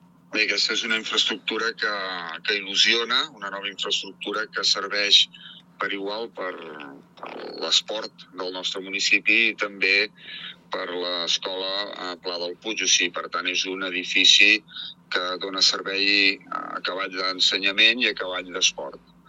Joan Carles Batanés, alcalde de Sant Fruitós, explicava els detalls al programa Esport i Punt.